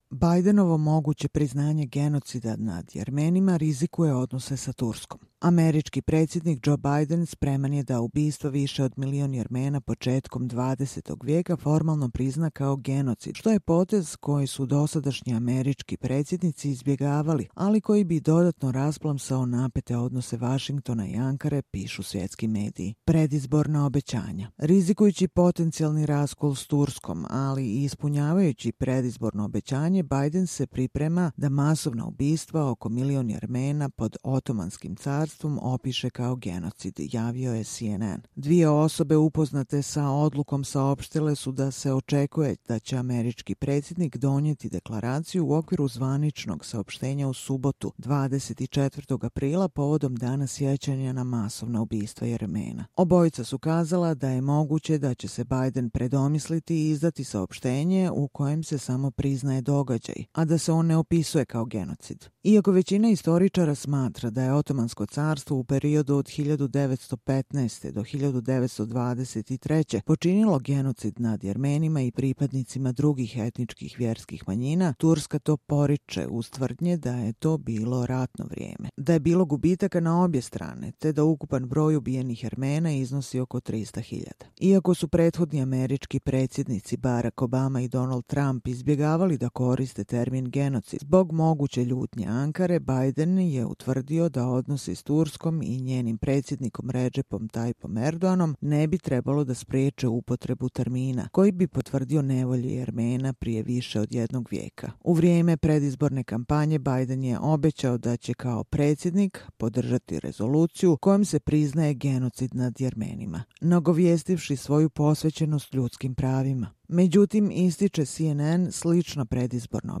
Čitamo vam: Bidenovo moguće priznanje genocida nad Jermenima rizikuje odnose s Turskom